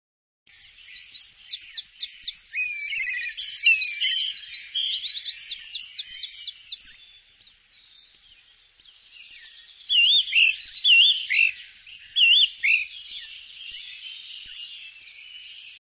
Vogelkonzert
Das Frühlingserwachen beschert uns jedes Jahr wieder ein einmaliges, vielstimmiges Vogelkonzert. Denn die Zugvögel kehren nach Mitteleuropa zurück.
Zusammen mit den ,,Daheimgebliebenen" stimmen sie in den Morgenstunden ihre schönsten Balz- und Reviergesänge an.
vogelkonzert.mp3